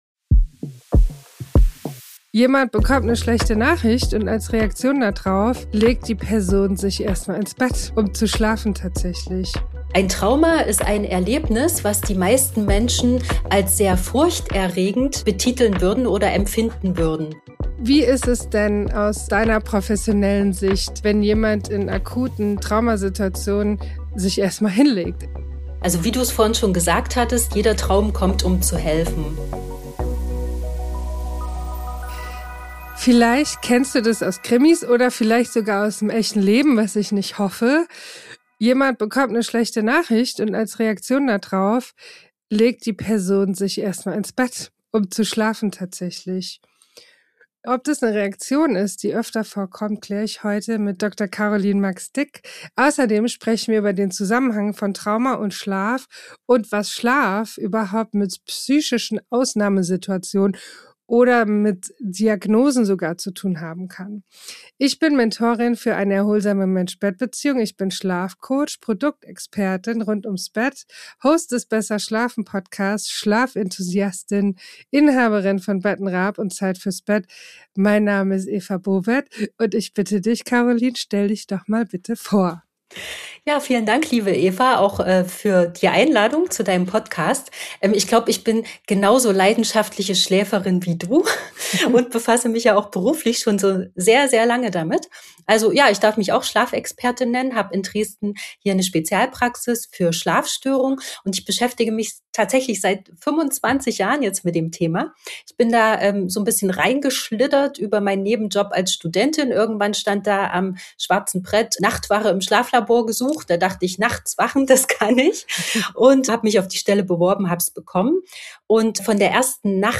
Psychotherapeutin und Expertin für Schlaf. Wir besprechen, warum Schlafprobleme nach einem Trauma so häufig auftreten und warum gerade der REM-Schlaf eine entscheidende Rolle dabei spielt, emotionale Wunden zu heilen.